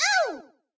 toadette_hurt_hard.ogg